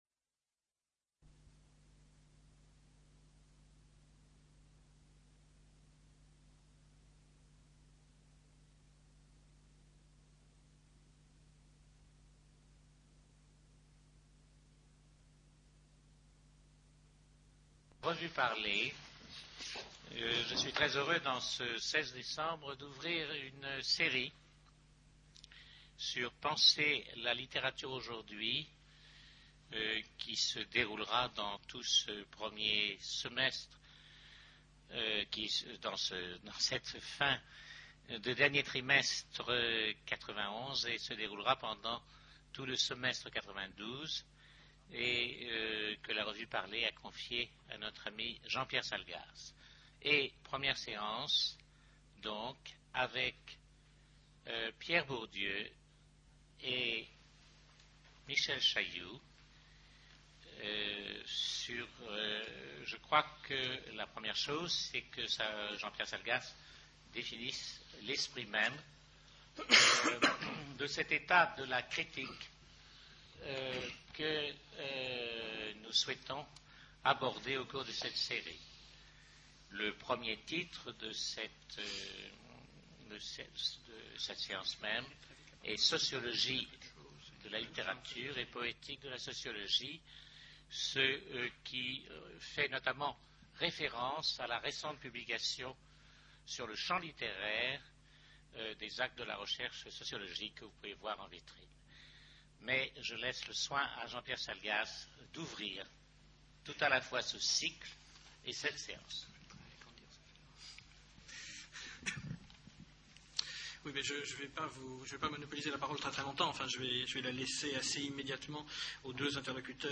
Centre Pompidou, le 16 décembre 1991